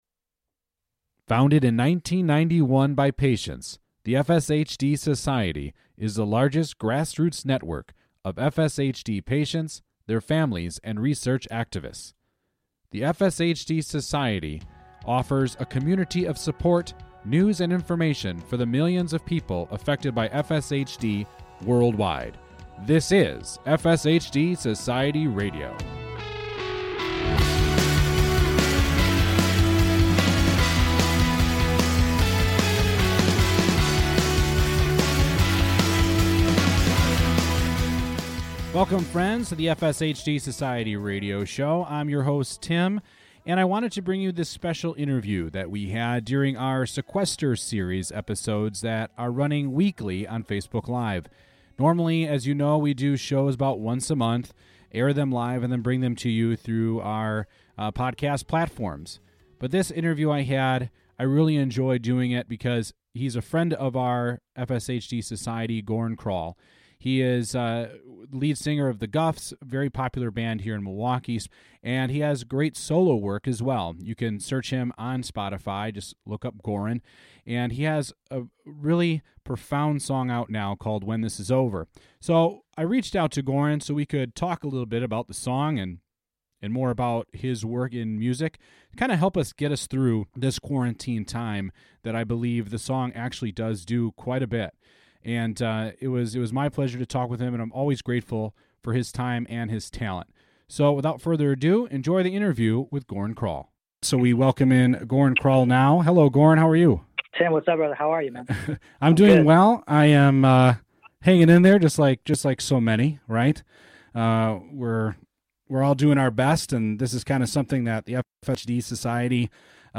singer-song writer